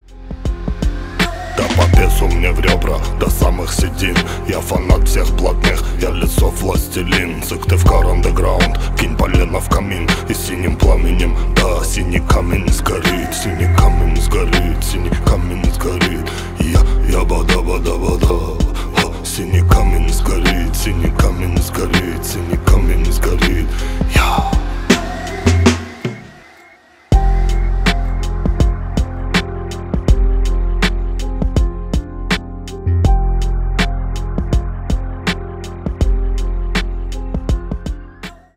блатные
рэп
хип-хоп